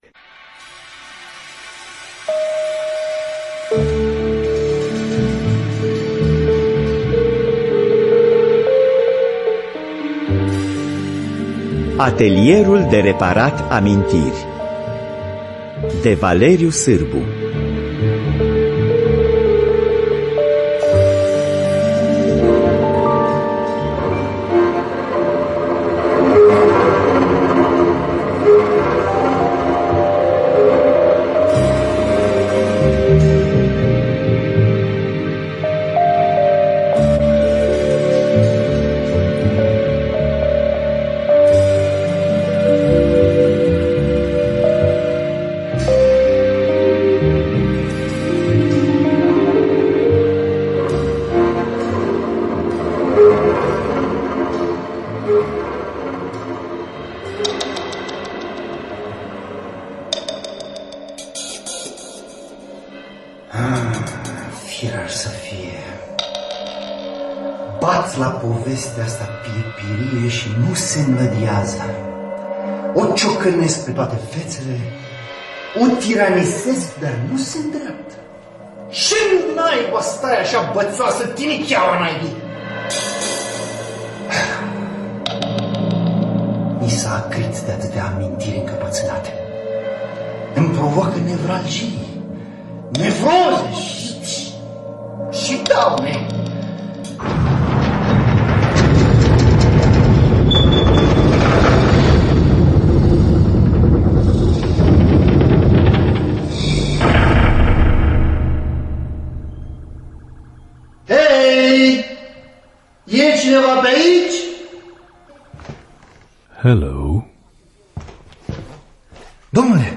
Valeriu Sarbu – Atelierul De Reparat Amintiri (2005) – Teatru Radiofonic Online